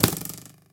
bowhit1.ogg